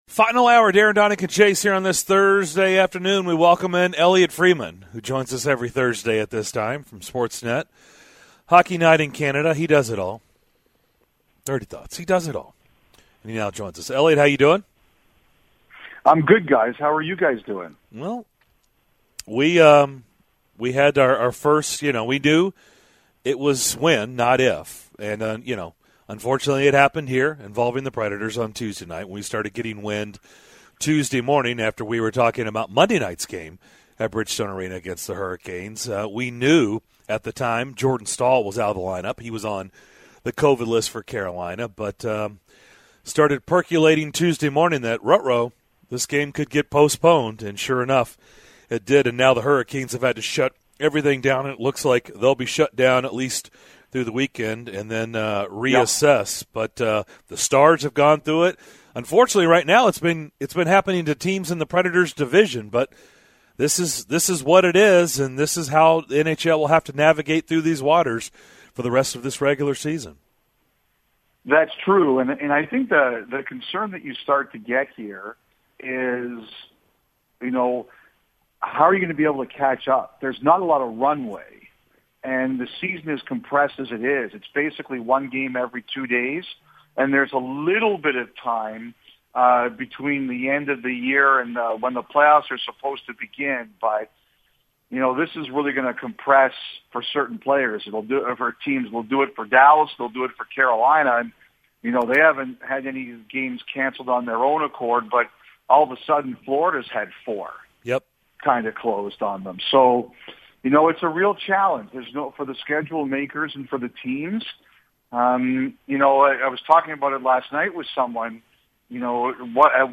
Sportsnet's Elliotte Friedman joined the show for his weekly hockey talk visit, the guys react to the news of Tennessee's hiring of Danny White to be their new A.D. and more in the final hour of Thursday's DDC!